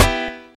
Snare (Criminal).wav